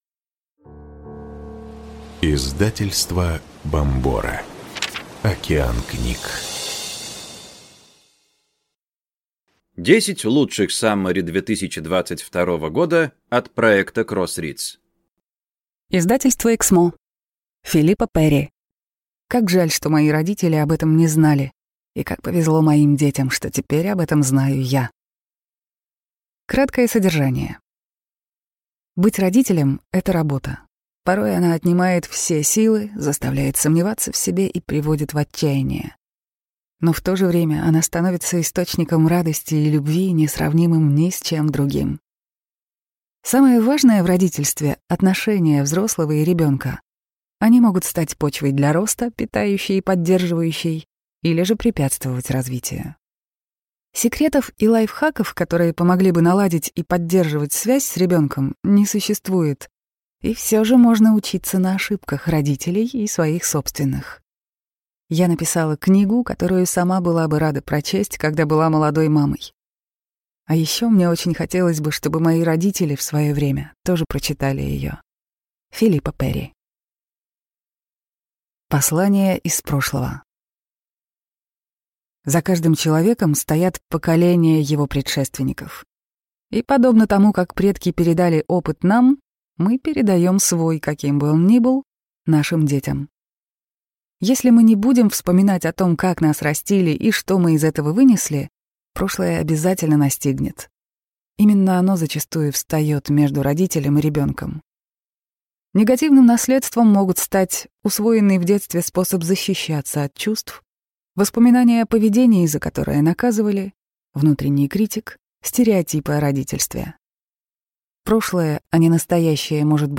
Аудиокнига 10 лучших саммари 2022 года | Библиотека аудиокниг